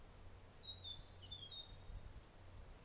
Tomtit chirping Direct link to audio file
In some cases, like the tomtit[2], I was able to find it and get a nice recording, but it flew away before I could capture a picture.
tomtit.wav